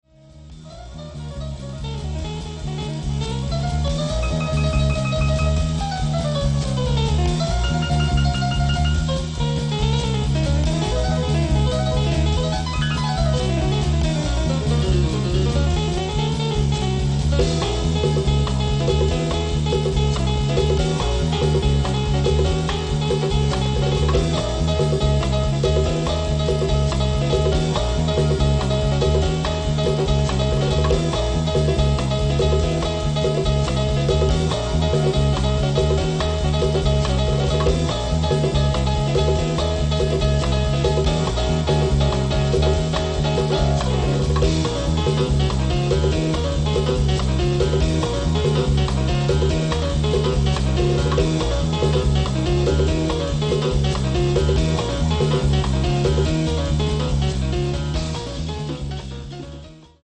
club classic